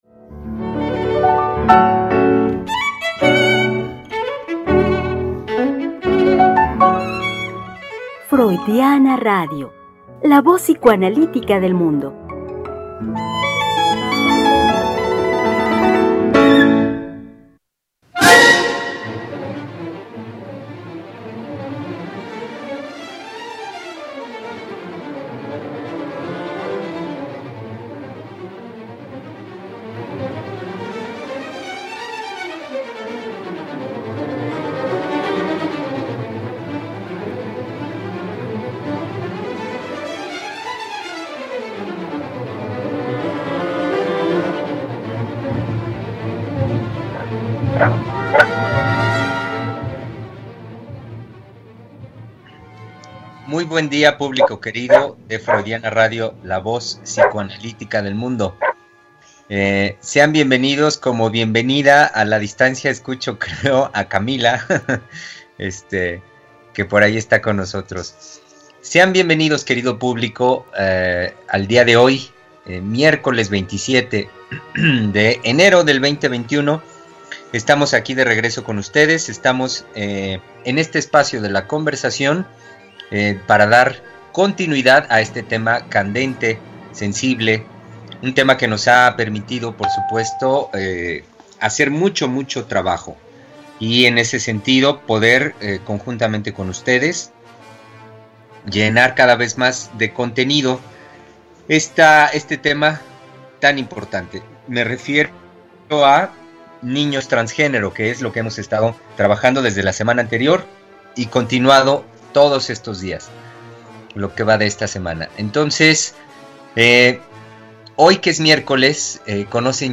Conversación
Programa transmitido el 27 de enero del 2021.